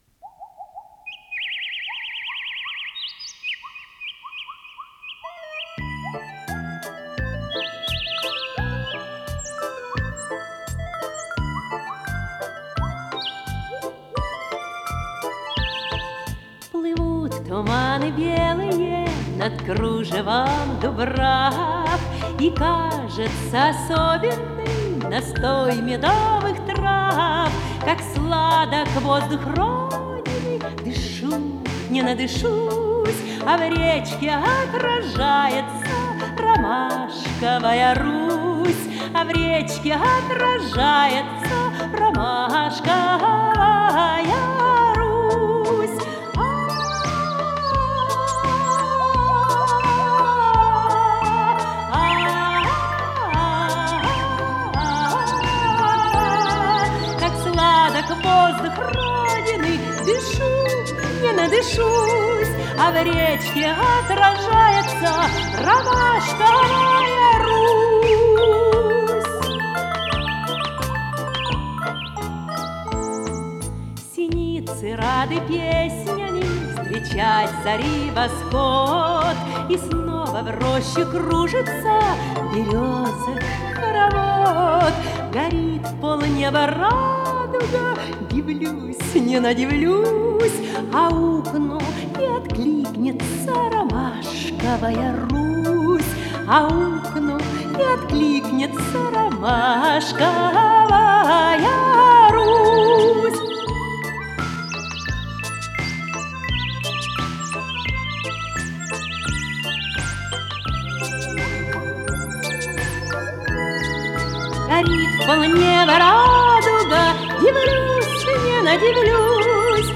ПодзаголовокЗапись с наложением
ВариантДубль моно